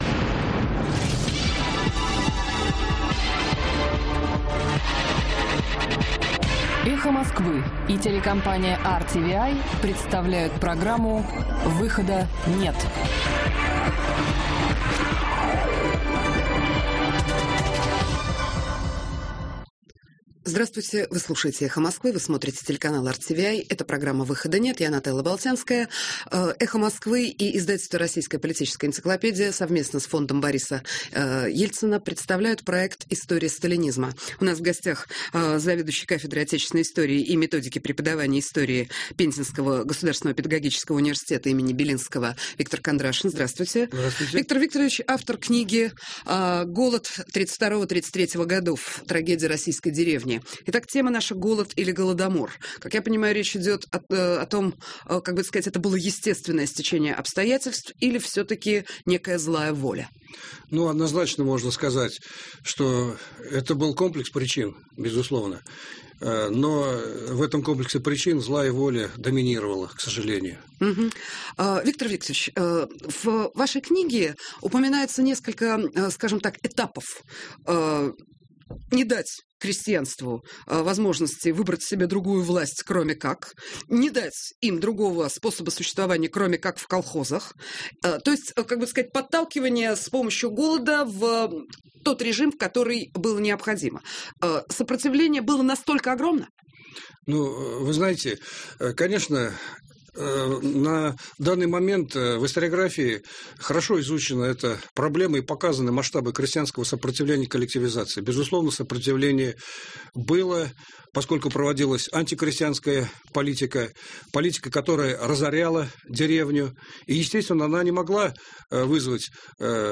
Эфир ведут - Нателла Болтянская, Евгений Ясин.